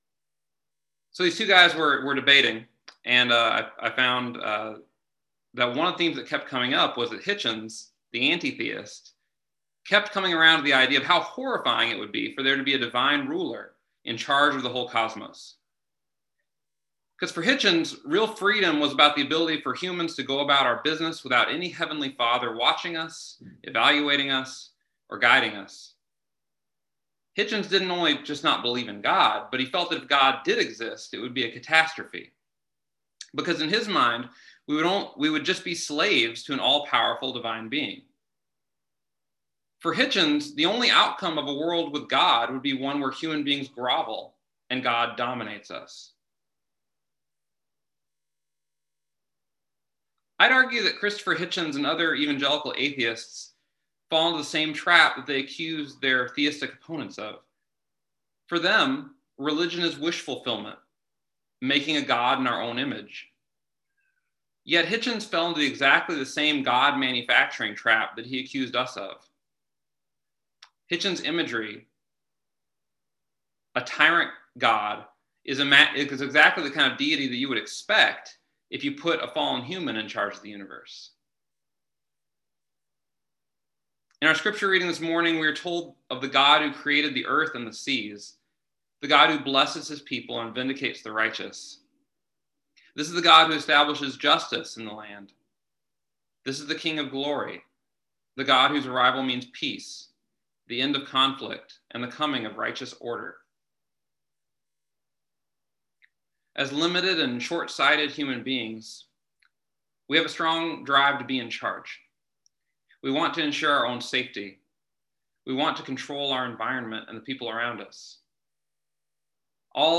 (I apologize for failing to start the recording soon enough.